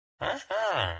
Villager Complete Trade